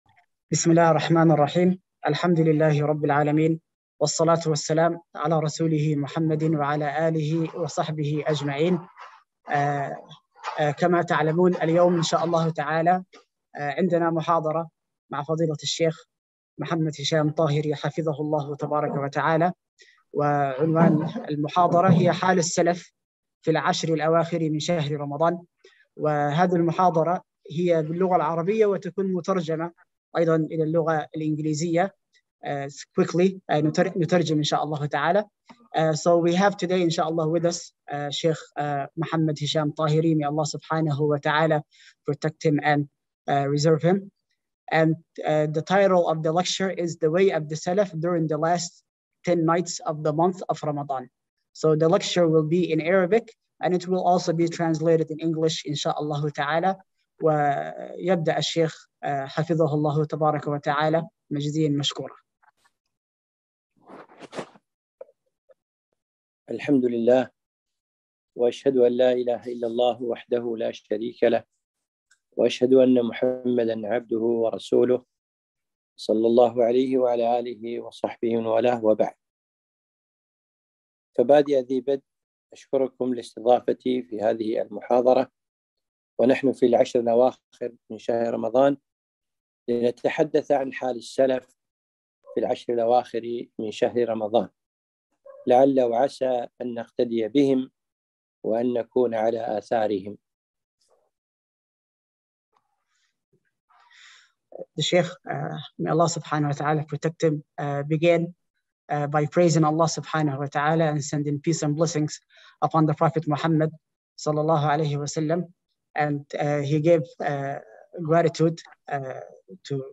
محاضرة - حال السلف في العشر الاواخر (ترجمة فورية للغة الانجليزية)